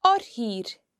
When R is not the first letter in a word and occurs next to e or i, it is considered slender, and can be heard in oir (an edge):